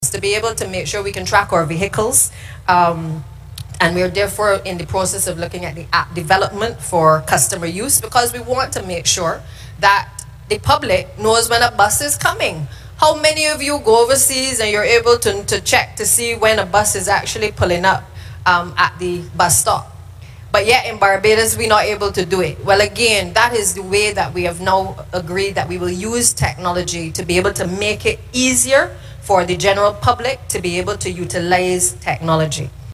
She spoke at the Transport Board’s 67th Anniversary service at the Collymore Rock Church of the Nazarene on Sunday.
Voice of: Minister and Minister of Transport, Works, and Water Resources, Santia Bradshaw